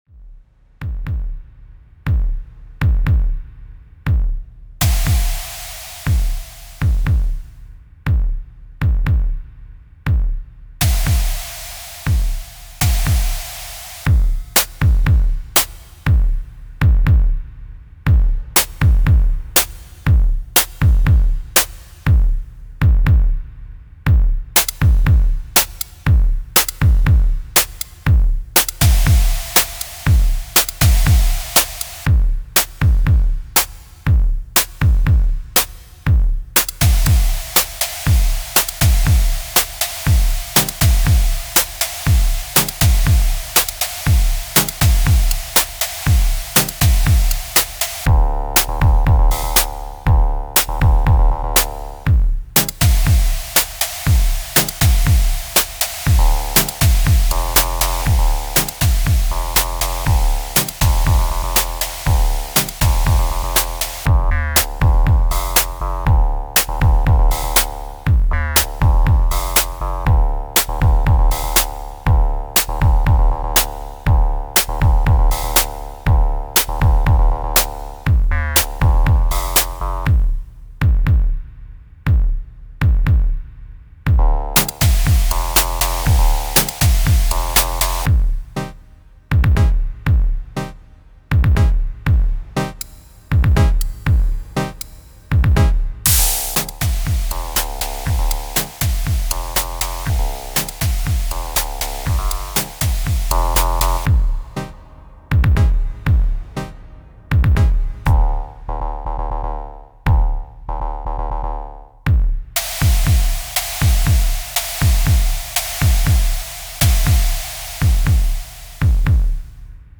Home > Music > Electronic > Instrumental > Techno > House